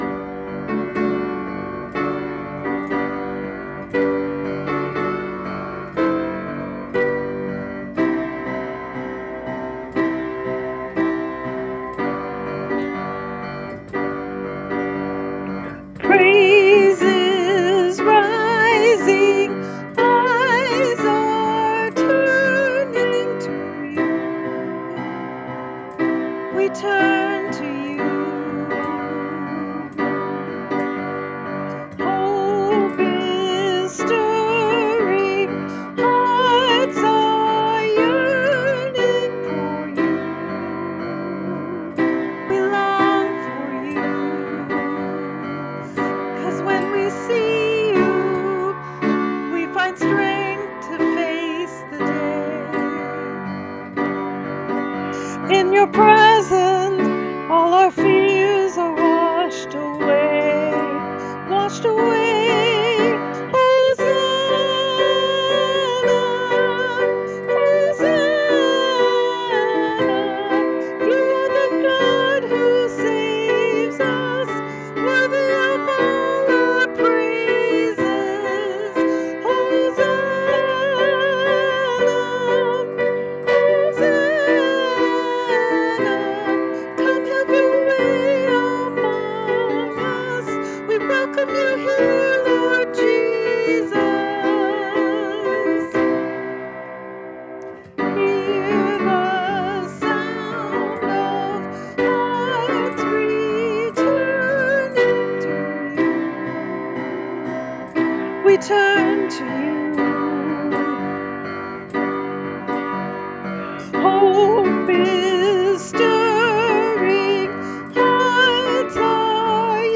Vocalist
Keyboard